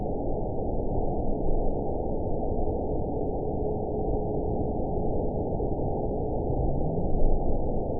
event 920537 date 03/28/24 time 22:27:47 GMT (1 year, 2 months ago) score 8.54 location TSS-AB07 detected by nrw target species NRW annotations +NRW Spectrogram: Frequency (kHz) vs. Time (s) audio not available .wav